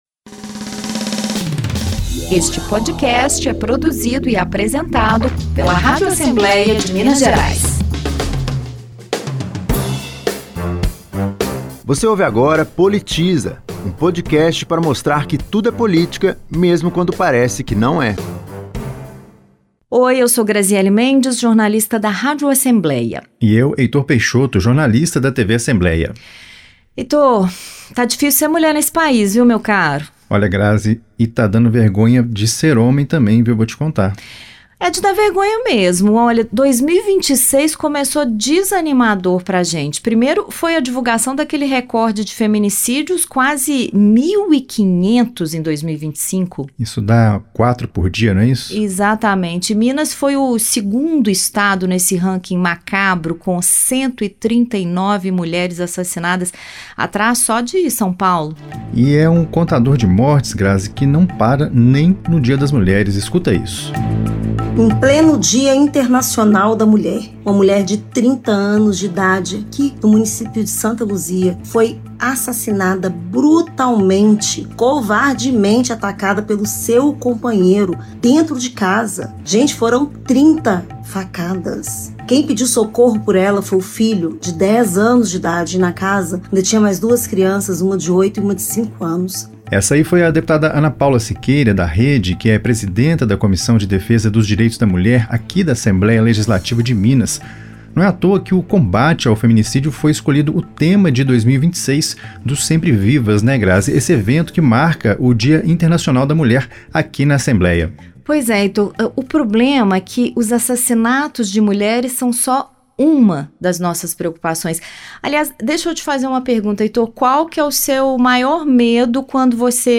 A procuradora de Justiça Fátima Borges é a convidada desta edição e alerta que todos somos responsáveis por identificar sinais de abuso.